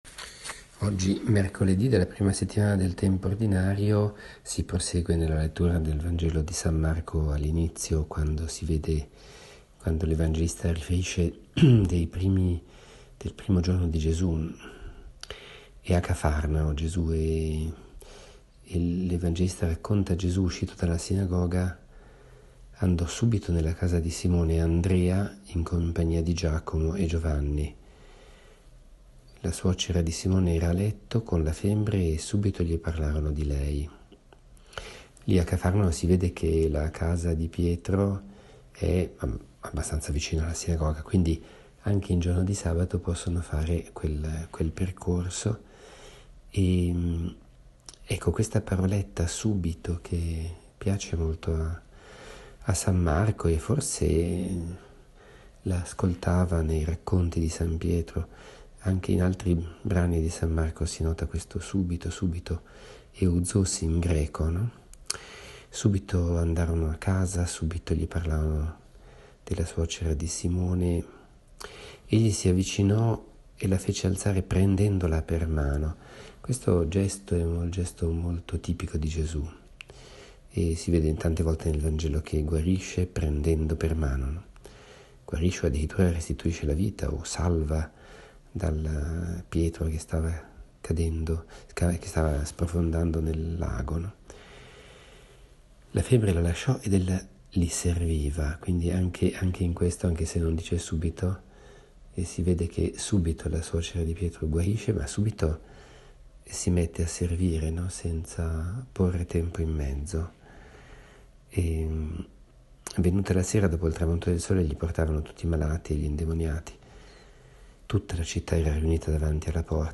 Commento al vangelo (Mc 1,29-39) di mercoledì 10 gennaio 2018, mercoledì della I settimana del Tempo Ordinario.